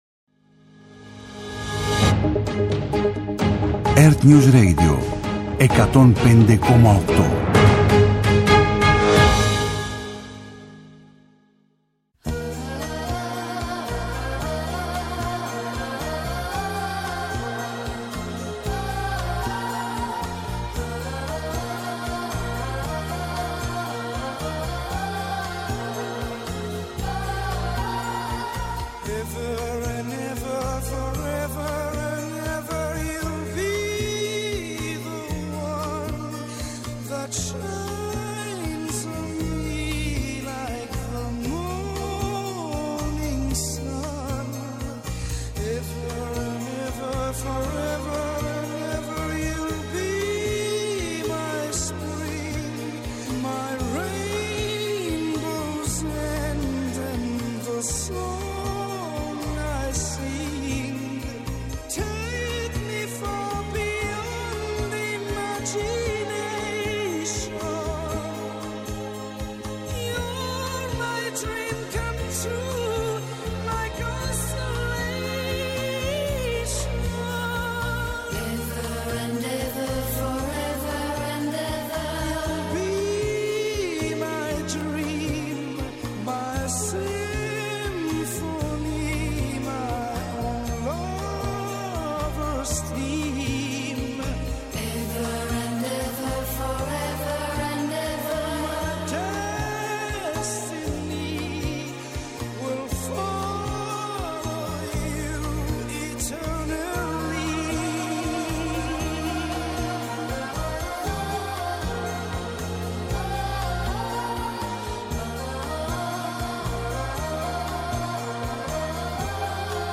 12:00-13:00, στο ΕΡΤNews Radio 105,8.